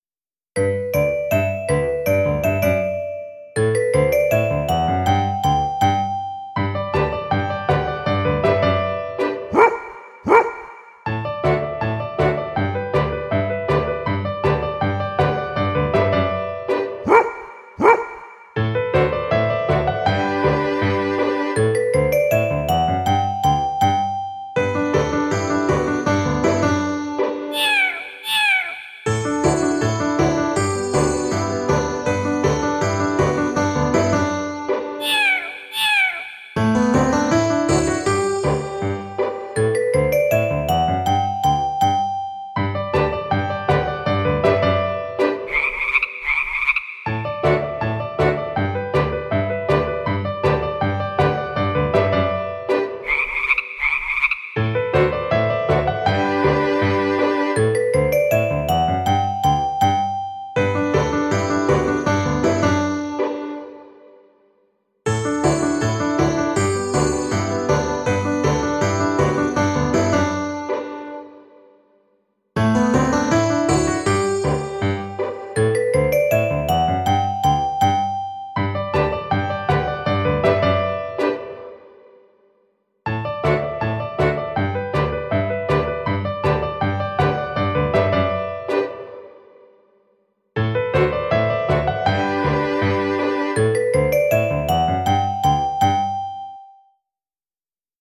• + 2x audio CD – s pesničkami (